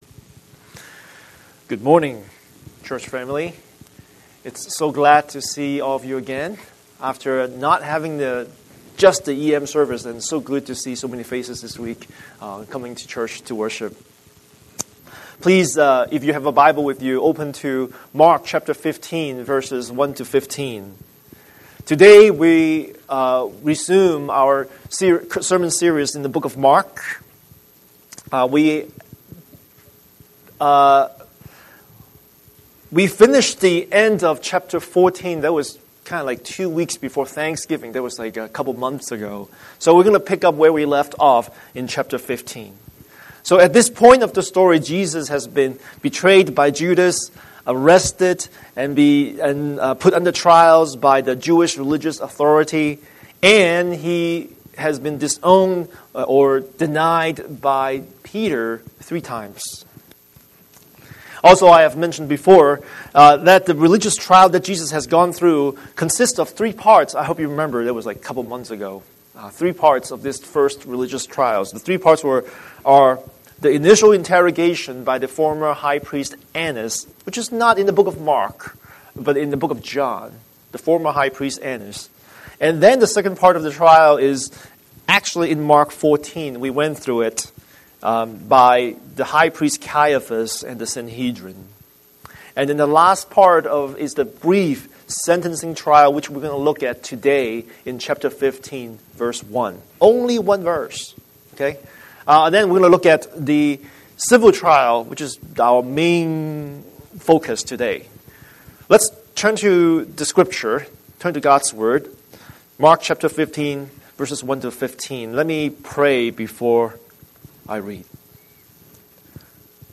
Scripture: Mark 15:1–15 Series: Sunday Sermon